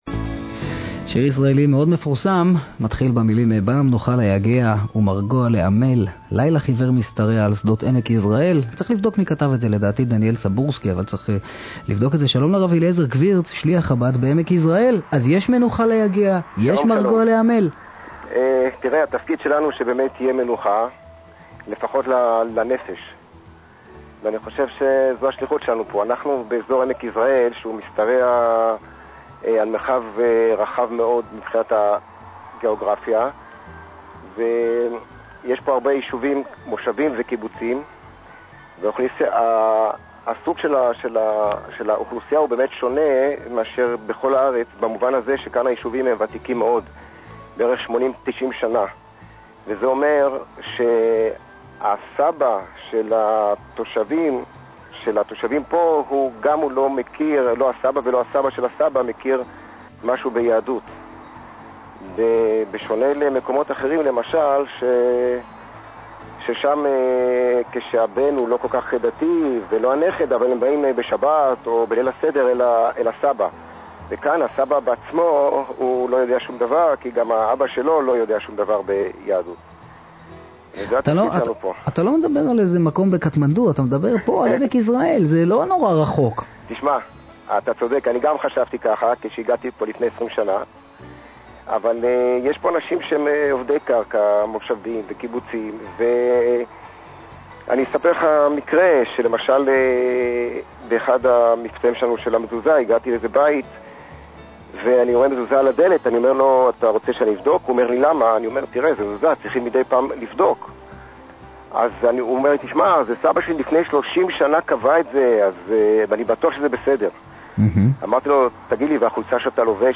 ראיון עם שליח חב"ד